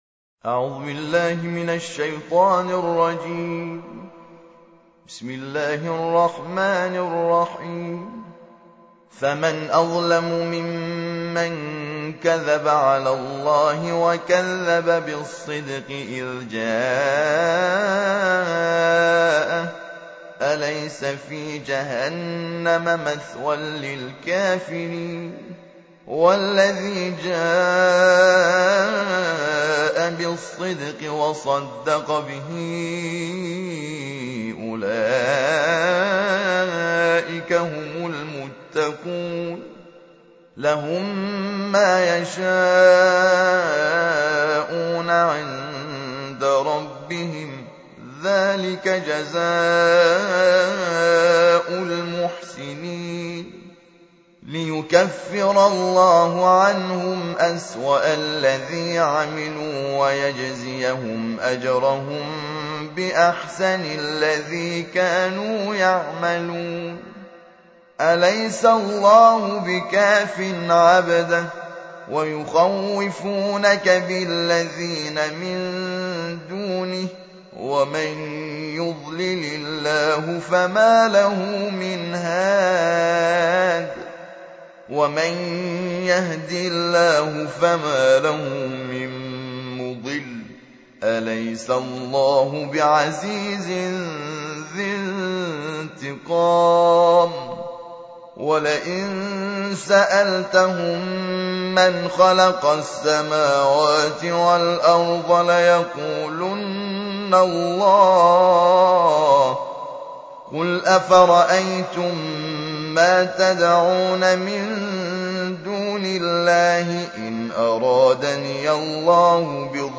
تکیه | ترتیل جزء بیست و چهارم قرآن کریم
ترتیل جزء بیست و چهارم قرآن کریم - حامد شاکر نژاد با ترافیک رایگان